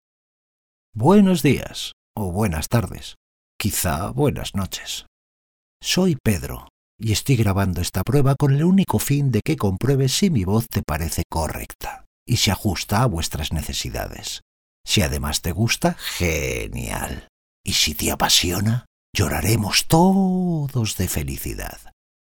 Male
Spanish - Spain (Castilian)
Adult (30-50), Older Sound (50+)
A voice with many nuances.
A dramatic, passionate, chilling, tender, kind, thoughtful voice ... whatever you need at all times.
Narration
Studio Quality Sample
Just My Voice